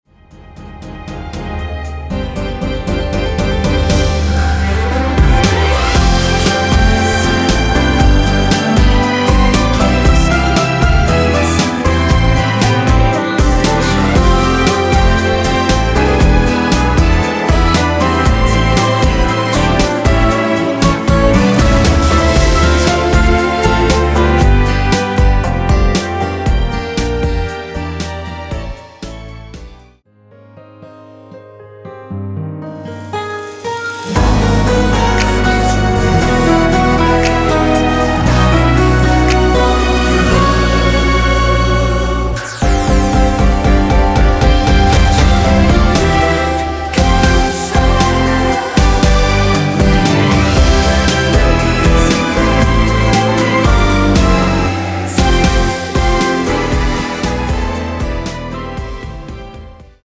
원키 코러스 포함된 MR입니다.(미리듣기 참조)
Am
앞부분30초, 뒷부분30초씩 편집해서 올려 드리고 있습니다.
중간에 음이 끈어지고 다시 나오는 이유는